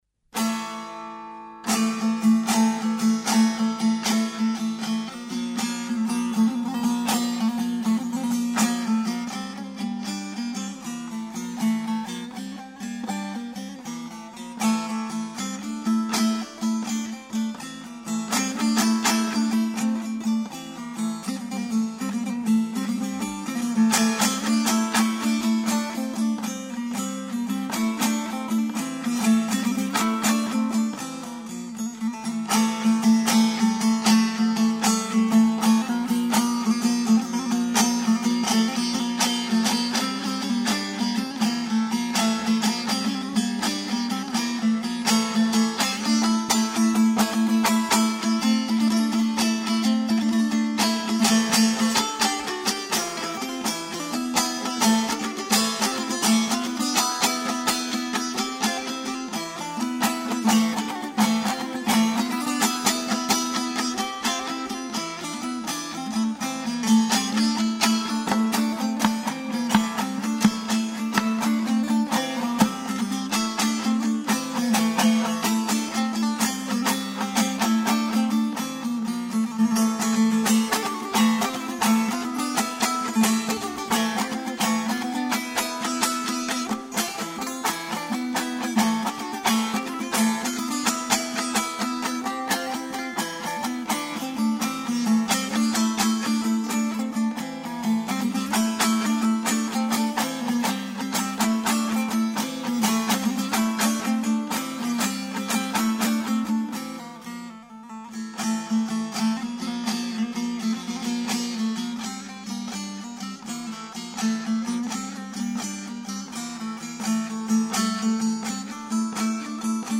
موسيقي محلي شمال خراسان شايد تنها نوع باقي مانده از موسيقي روايي در كشور ما باشد. نوعي از موسيقي كه با روايت يك داستان همراه است.